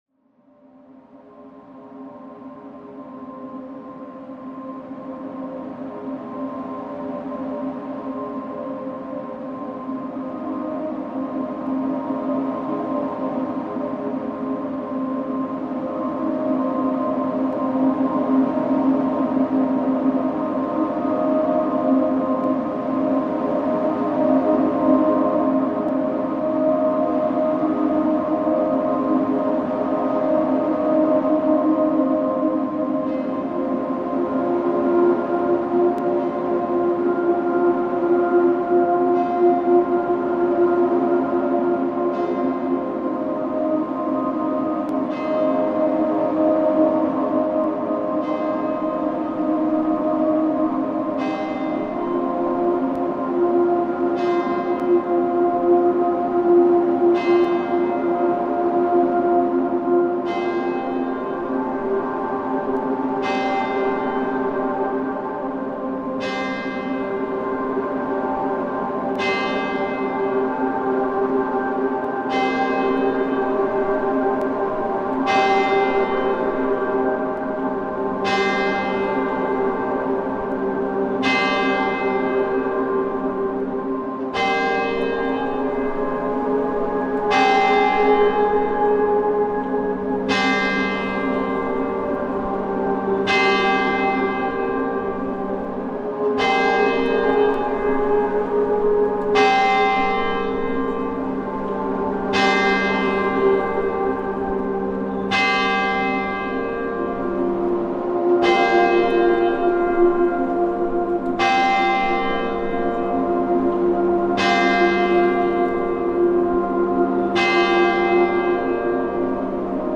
Bremerhaven bell sounds reimagined